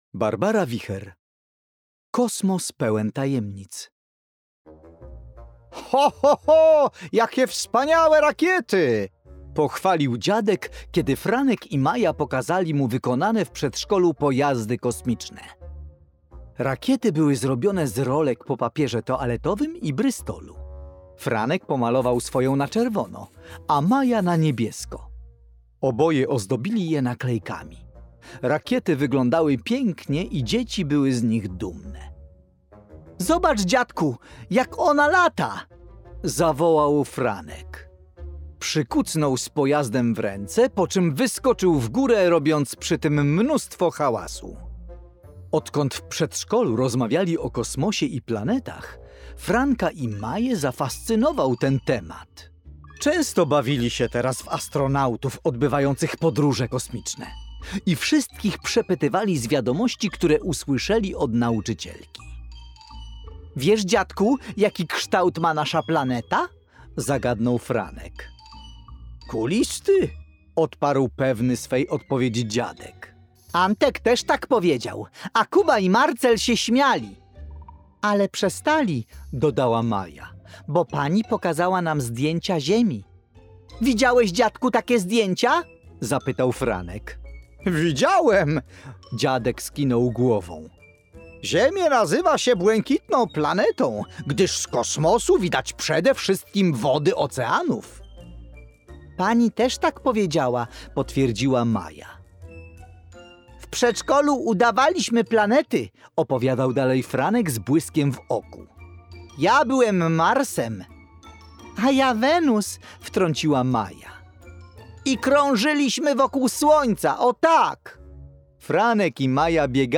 opowiadanie „Kosmos pełen tajemnic” - EDURANGA